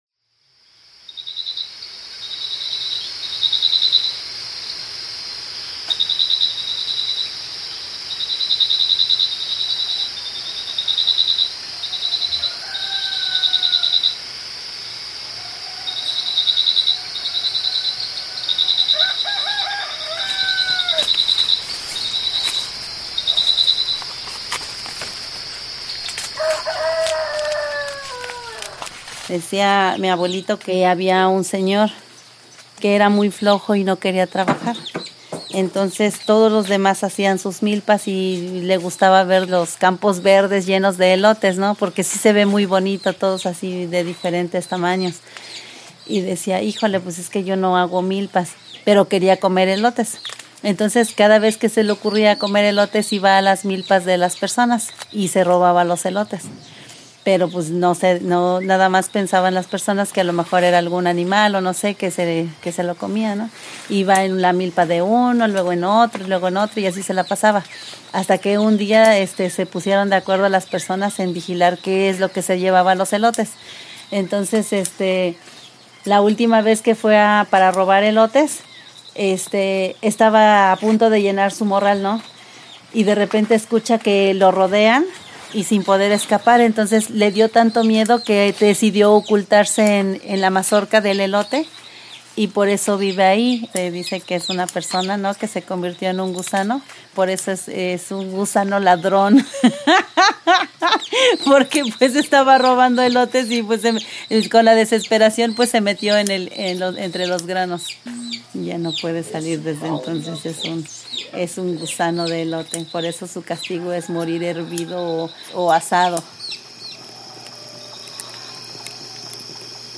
Esta narración está acompañada por el sonido de la lumbre de la casa.